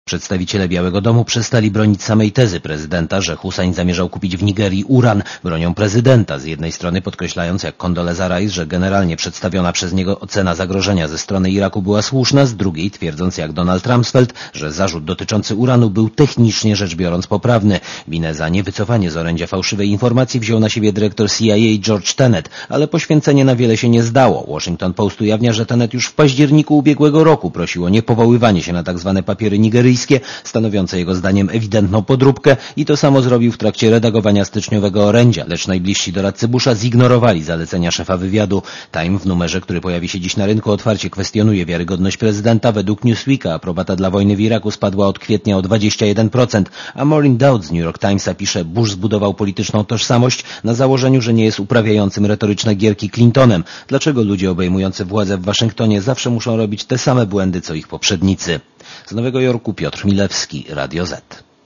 Posłuchaj relacji korespondenta Radia Zet (240 KB)